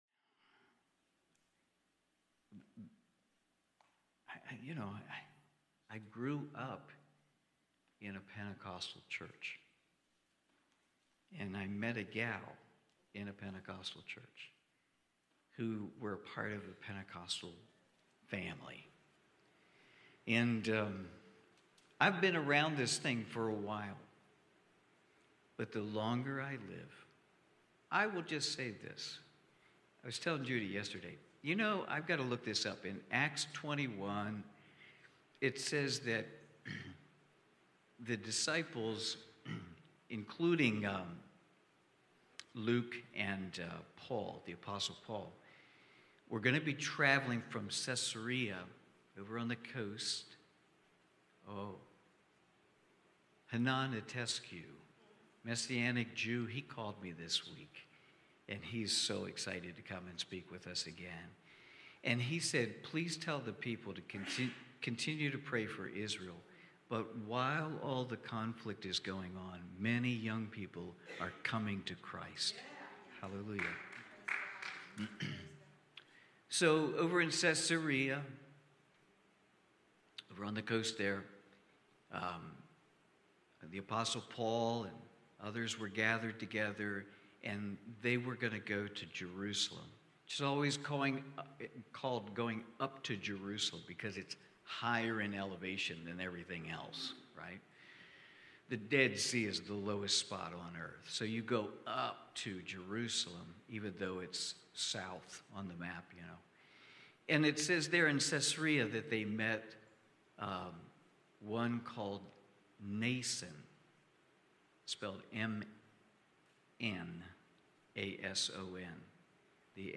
Sunday morning service, livestreamed from Wormleysburg, PA.